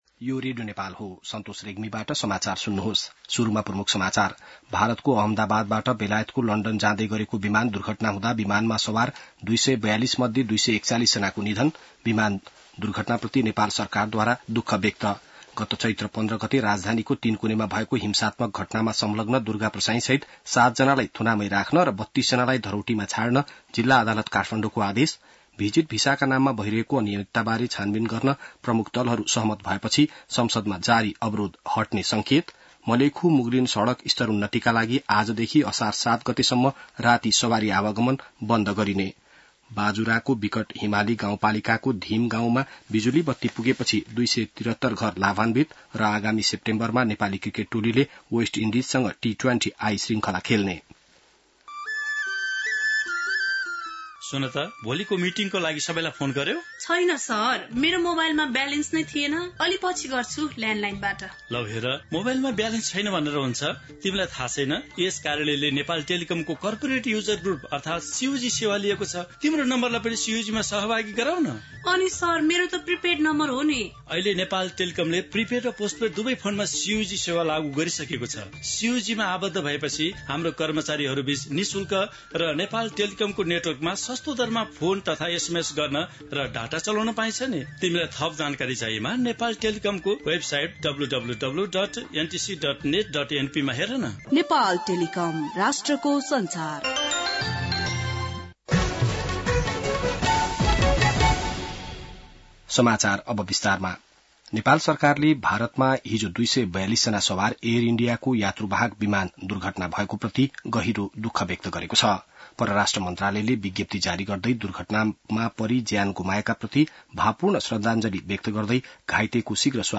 बिहान ७ बजेको नेपाली समाचार : ३० जेठ , २०८२